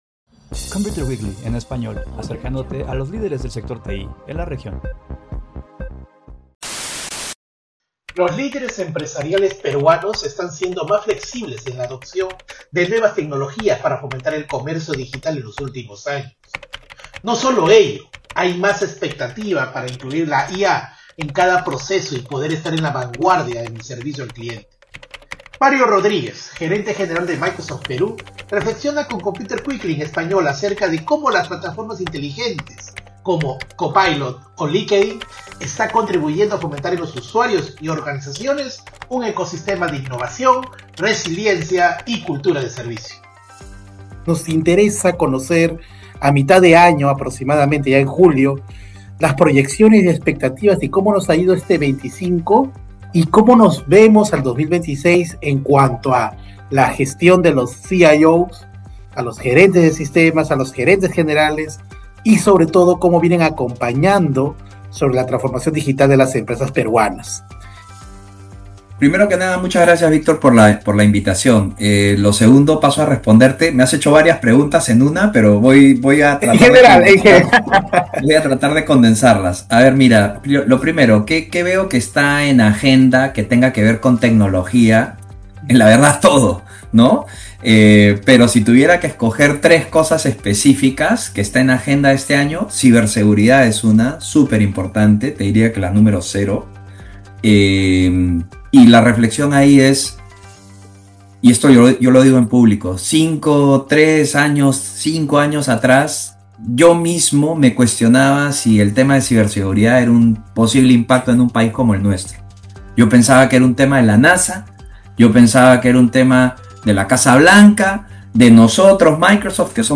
en conversación con ComputerWeekly en Español